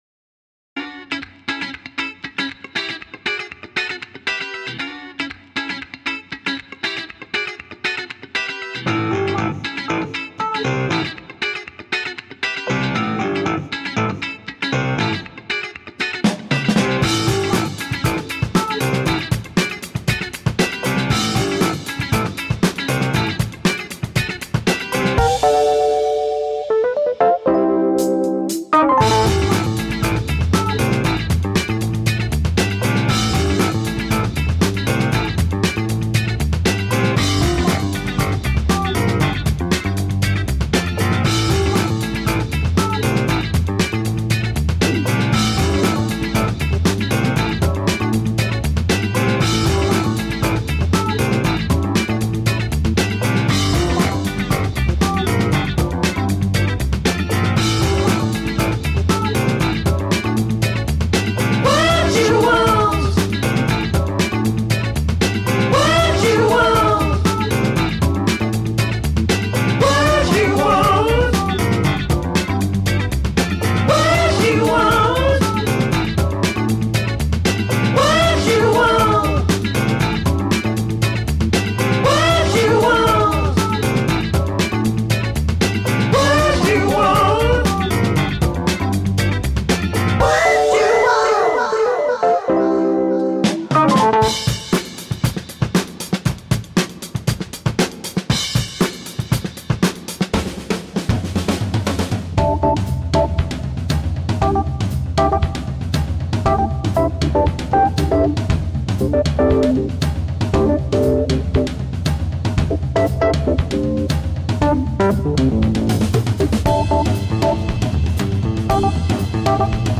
A new take on old funk.
Tagged as: Alt Rock, Funk, Rock, Electro Pop